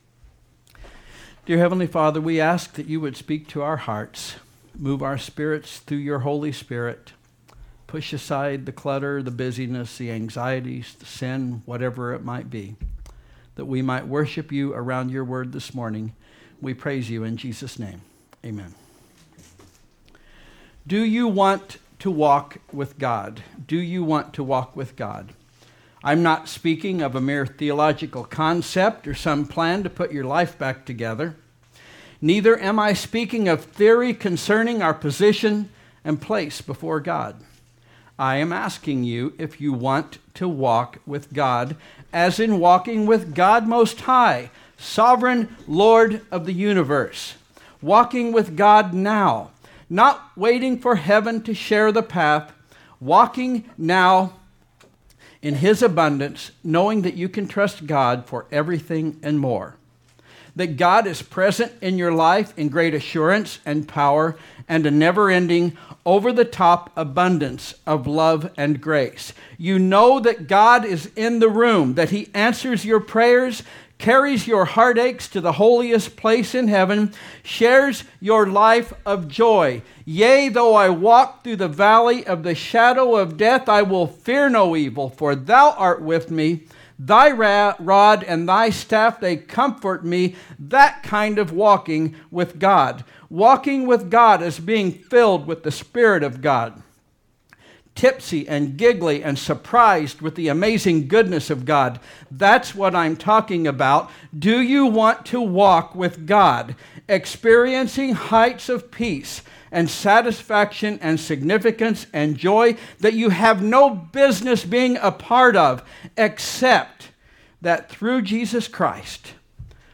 SUNDAY AM:
Here is the audio (MP3 & WMA) for both services on Sunday.